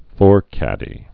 (fôrkădē)